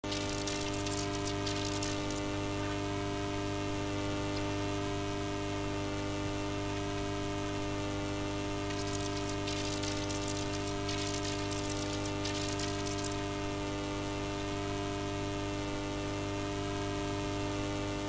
ラインへ繋げ、彼是ヘッド REC部 もと モーター回せば ノイズは酷いが グッダグダ の ヘロヘロテープ から胡散臭い
リピート音 が聴こえる、 雑音は シールド無しやからしょうがないが 再生側 だけの ヘッドで聞けないのは困ったの。